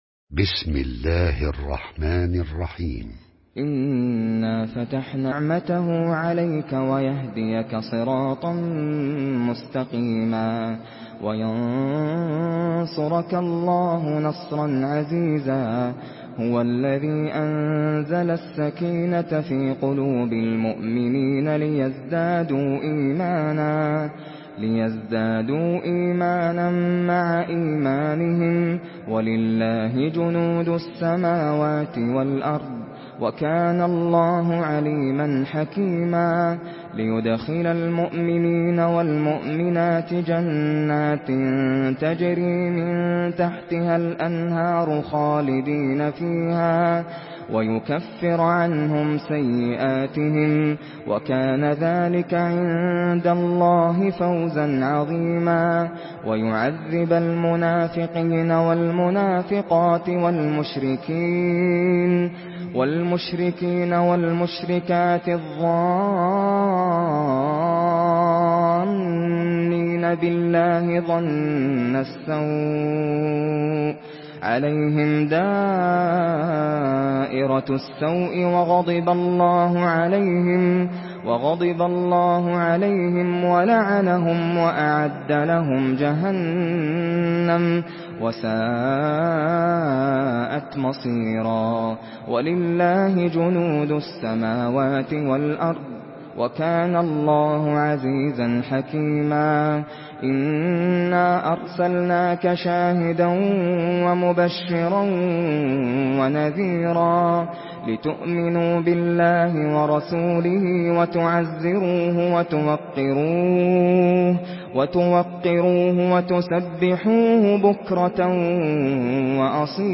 Surah Al-Fath MP3 by Nasser Al Qatami in Hafs An Asim narration.
Murattal Hafs An Asim